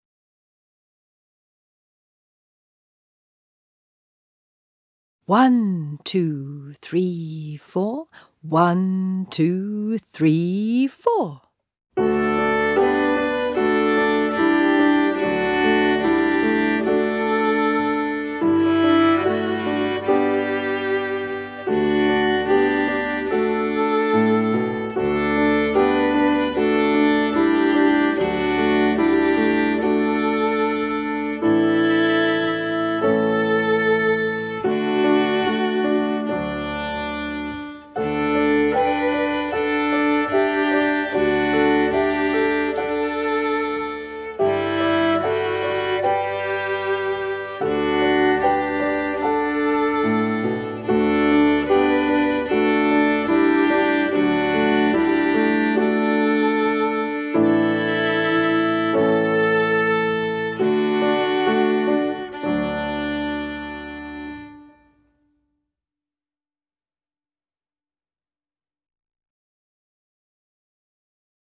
Los audios de las canciones están a continuación de las partituras, solo hay una versión con el violín tocando. Escuchad bien al piano y palmead el ritmo para que sepáis donde entrar a tocar.